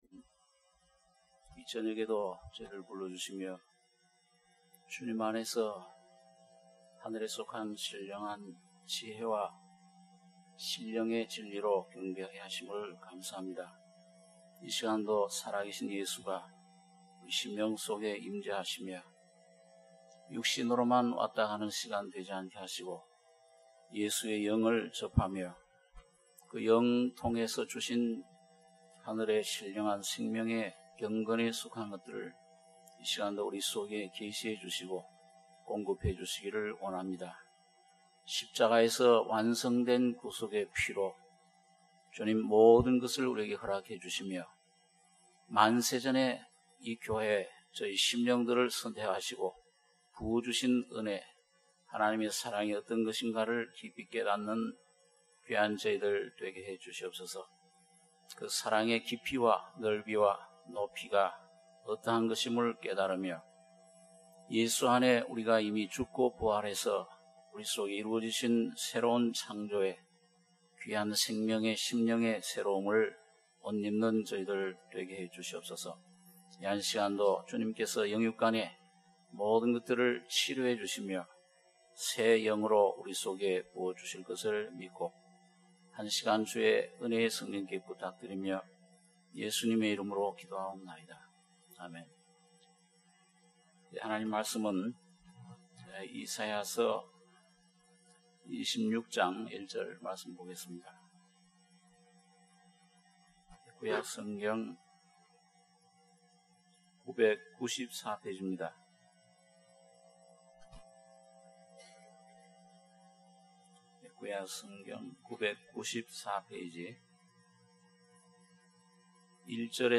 수요예배 - 이사야 26장 1절-10절